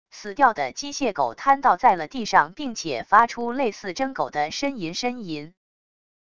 死掉的机械狗摊到在了地上并且发出类似真狗的呻吟呻吟wav音频